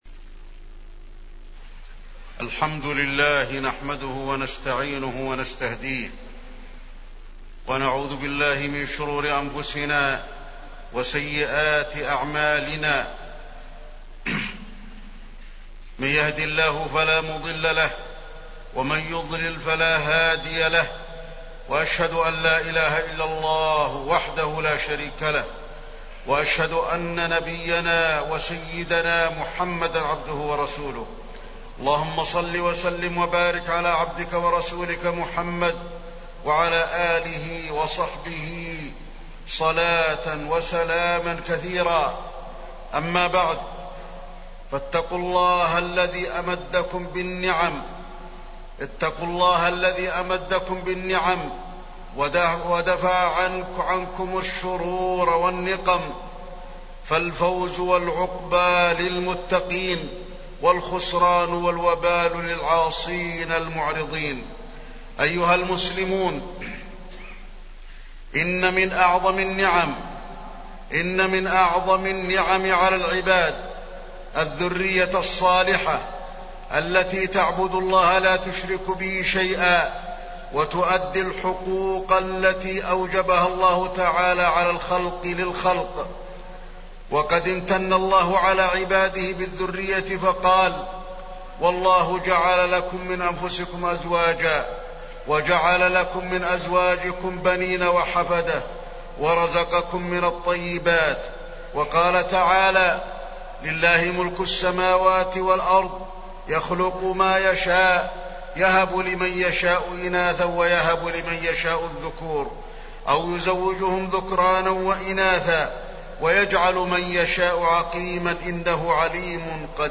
تاريخ النشر ٢٠ ربيع الأول ١٤٢٩ هـ المكان: المسجد النبوي الشيخ: فضيلة الشيخ د. علي بن عبدالرحمن الحذيفي فضيلة الشيخ د. علي بن عبدالرحمن الحذيفي الذرية الصالحة